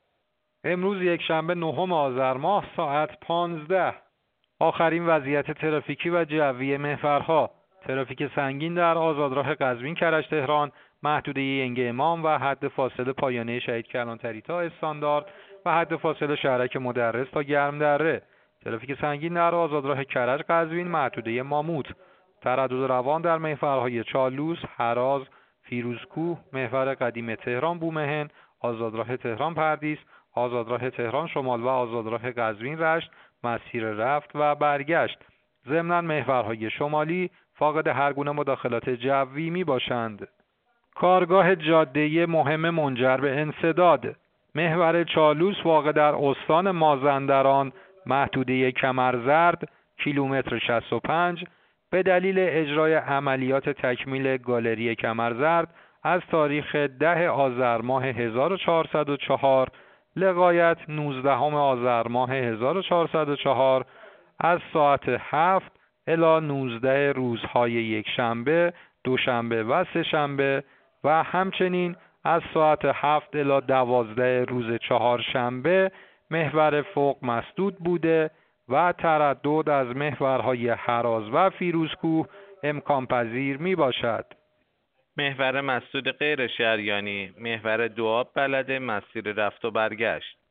گزارش رادیو اینترنتی از آخرین وضعیت ترافیکی جاده‌ها ساعت ۱۵ نهم آذر؛